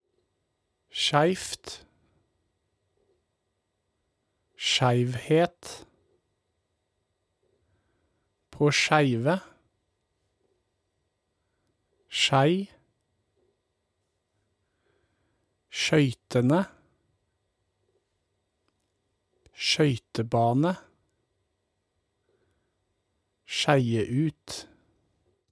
ʃ-lyd med SK: 11 Les ord – side 2 av 8 – Fokus